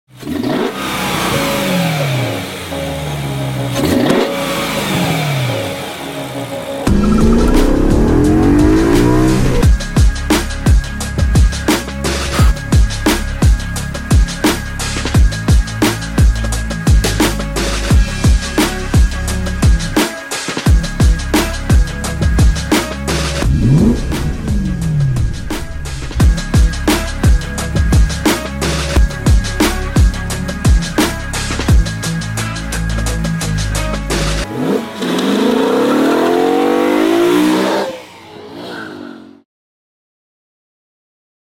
Headphones On 🎧 Pure S65 sound effects free download By ecstuning 1 Downloads 5 months ago 41 seconds ecstuning Sound Effects About Headphones On 🎧 Pure S65 Mp3 Sound Effect Headphones On 🎧 Pure S65 sounds, our plenum intake sure knows how to sing!